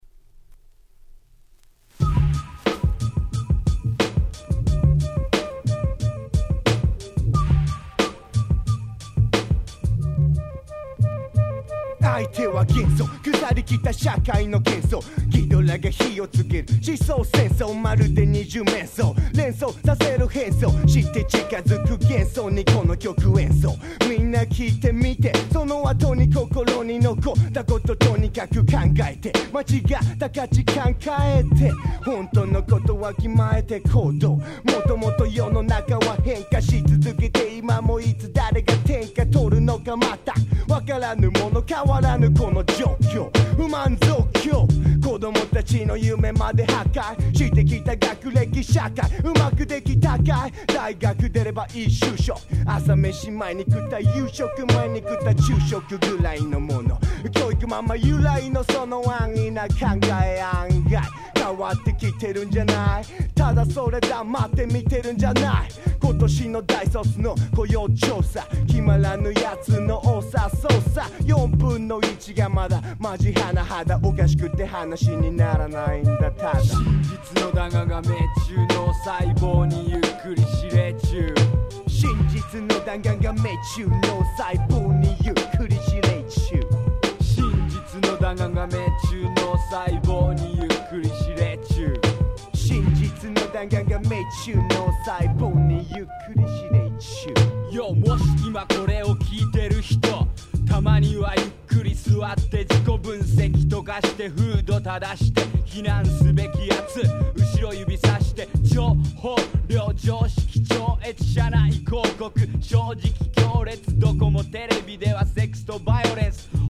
96' Japanese Hip Hop Classics !!
日本語ラップ